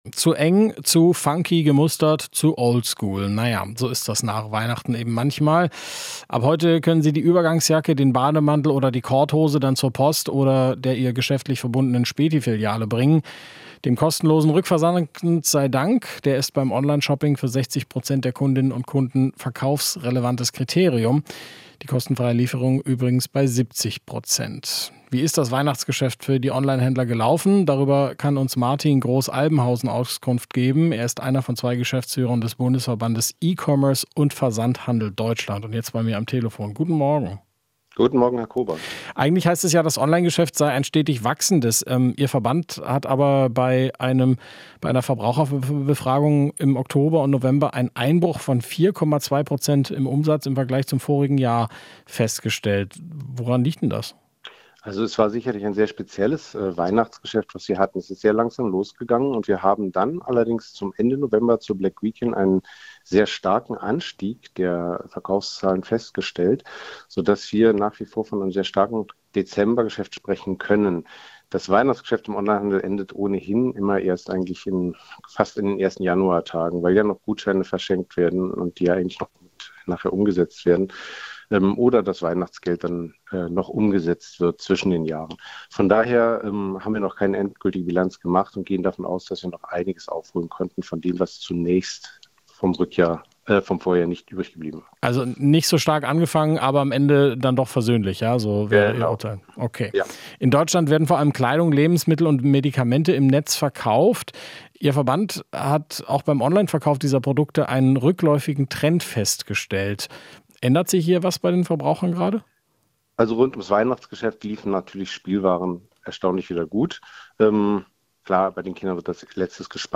Interview - Branchenverband: Es wird ausgesuchter und günstiger bestellt